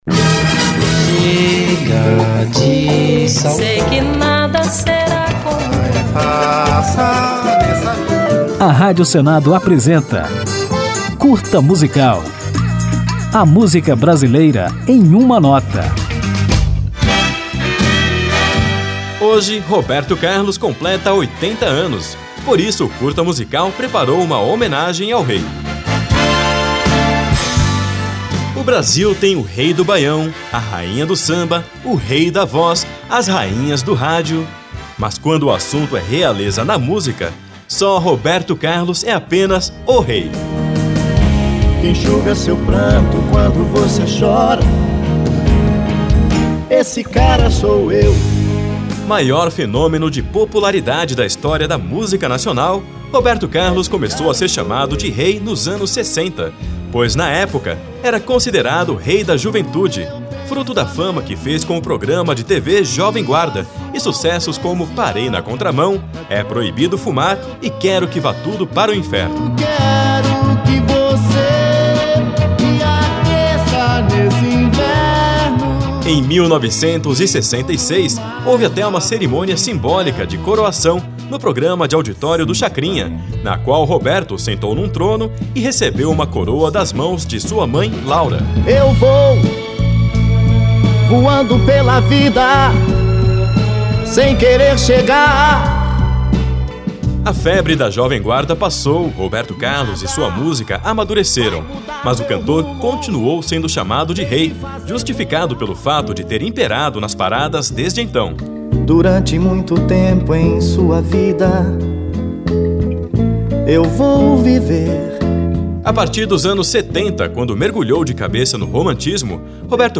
Ao final do programa, que relembrará seus grandes sucessos, ouviremos na íntegra a música Emoções, com Roberto Carlos, fechando com chave de ouro nossa homenagem.